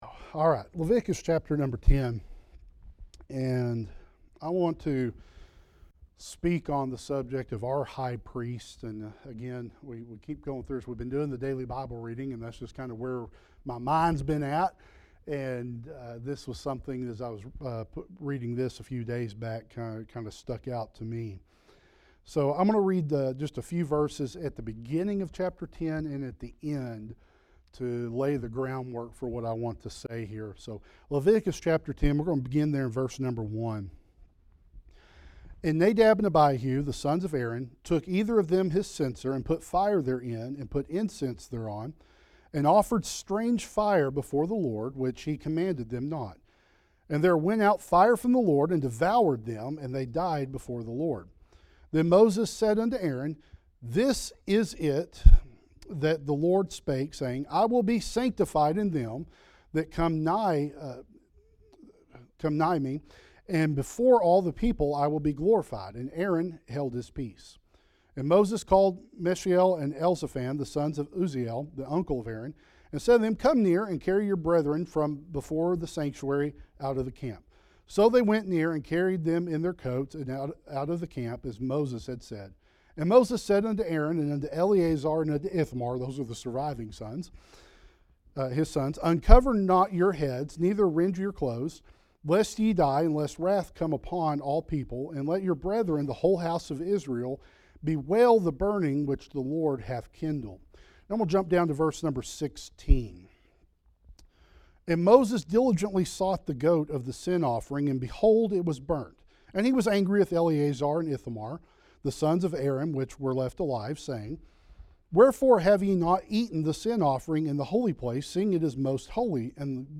Sermon – “Our High Priest”
sermon-our-high-priest